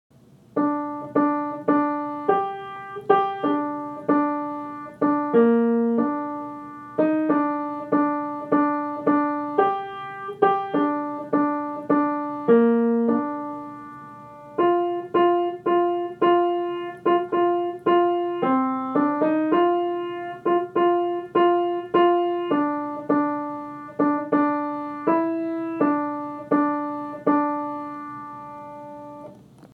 remember_o_thou_man_-_alto_1.mp3